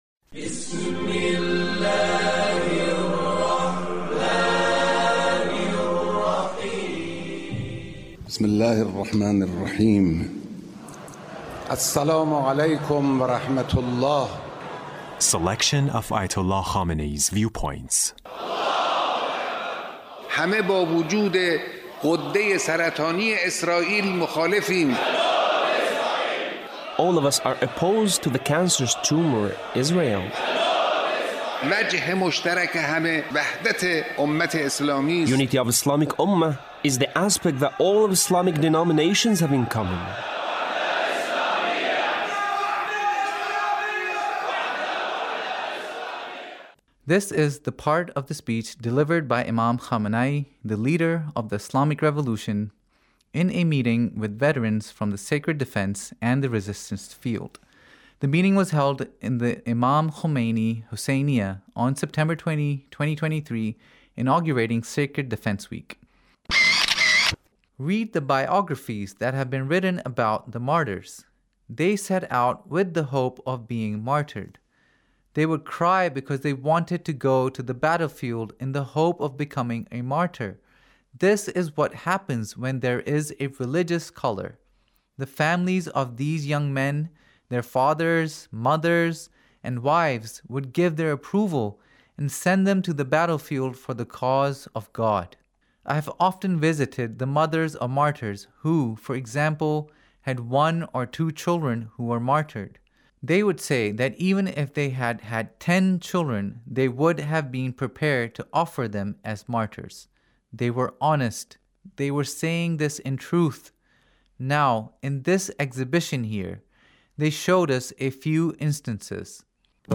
Leader's Speech about Sacred defense